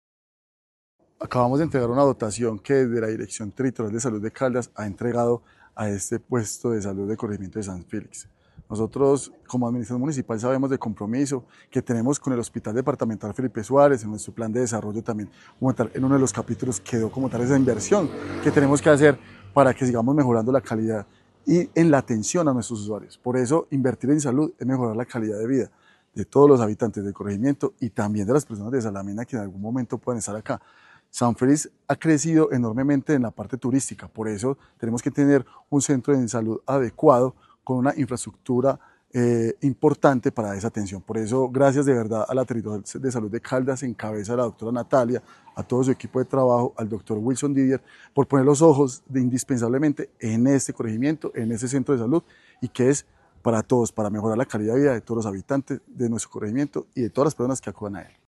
Manuel Fermín Giraldo, Alcalde de Salamina.